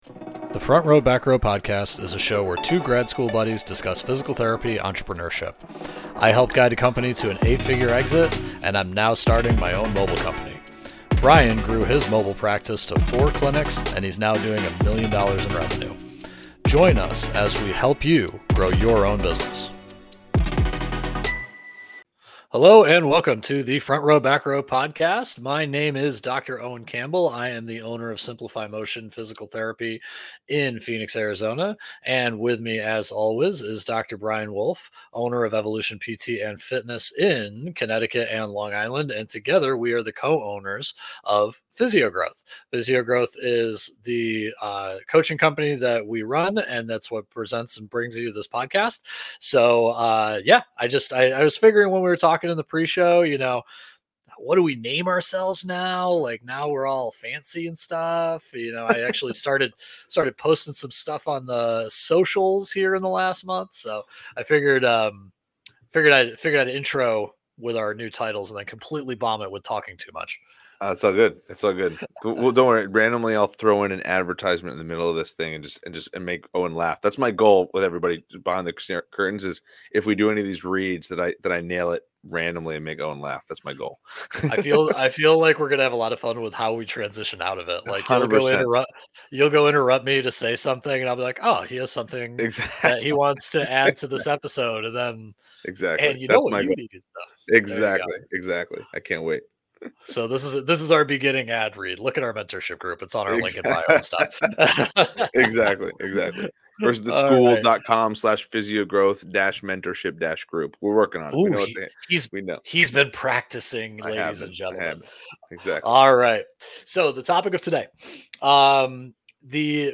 conversation about getting a hot lead to convert into a paying patient or client for a physical therapy practice